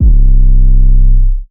REDD 808 (4).wav